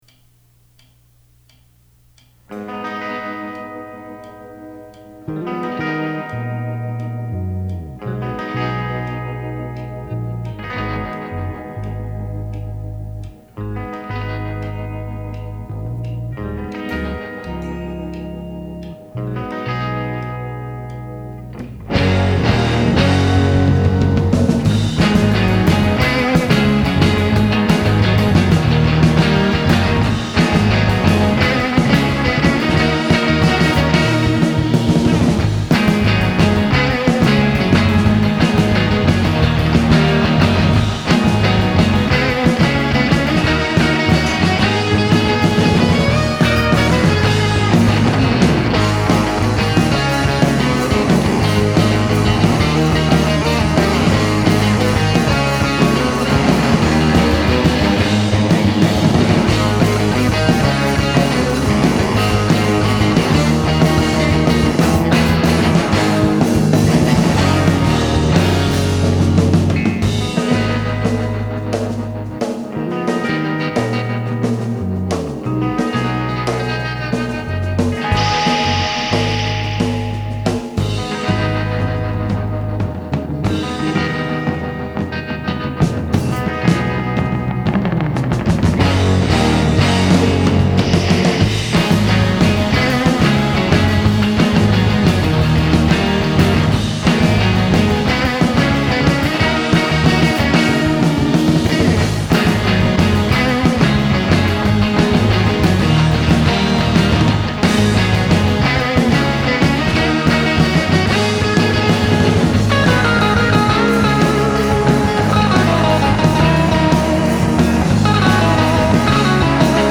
conceptual psychedlic thrash-surf explosion
(instrumental)